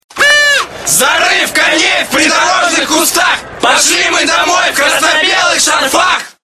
Назад в Футбольные кричалки Файл Зарыв коней в придорожных кустах....